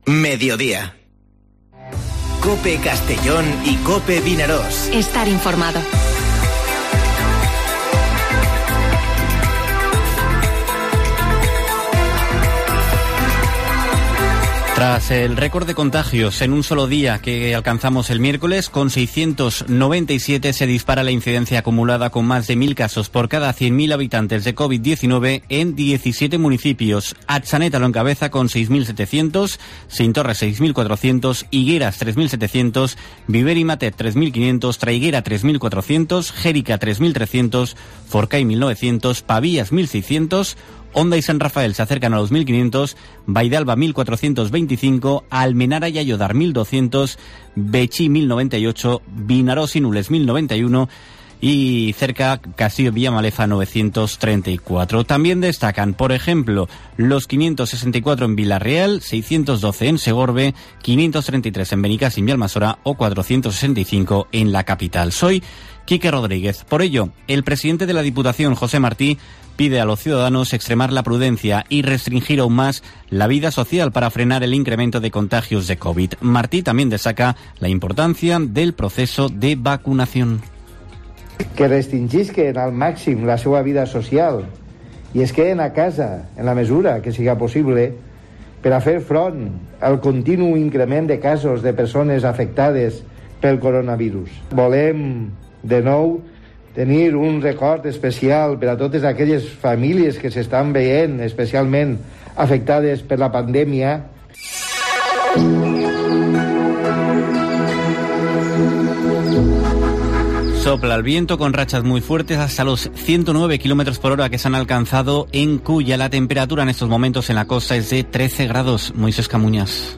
Informativo Mediodía COPE en la provincia de Castellón (15/01/2021)